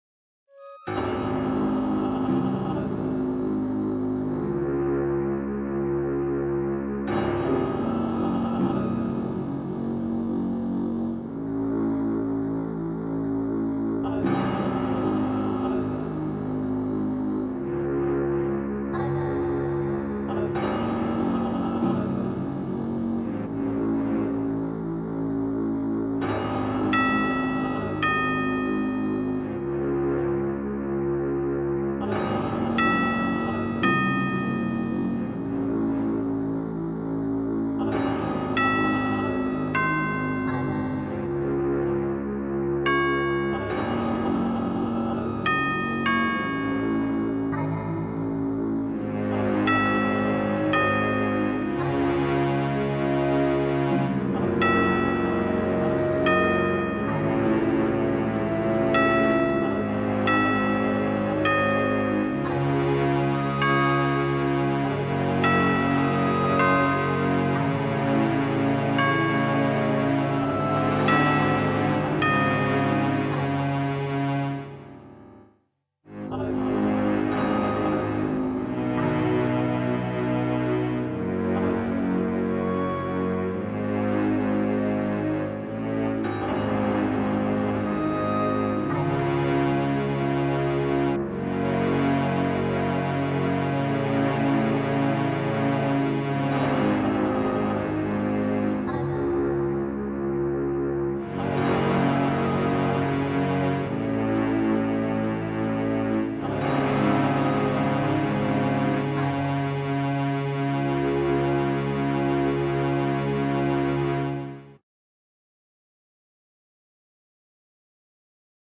BG Music